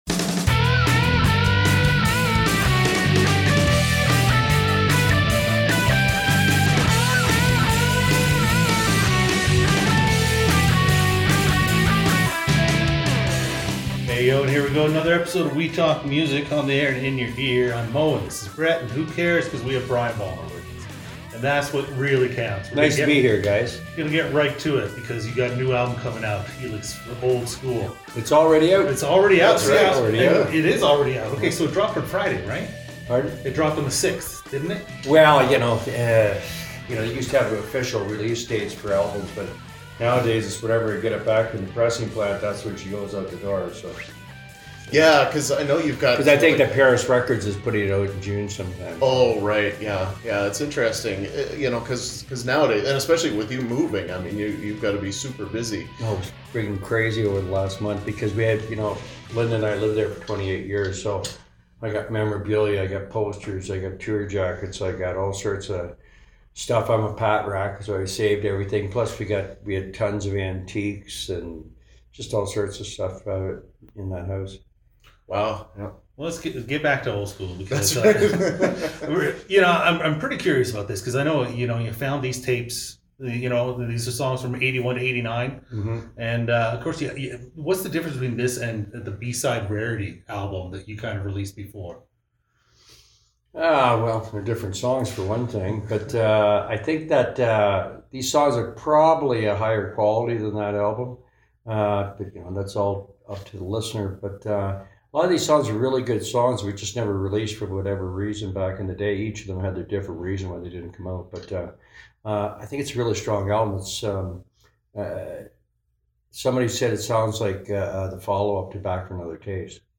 WTMusic_Interview-Brian_Vollmer_of_Helix_Old_School.mp3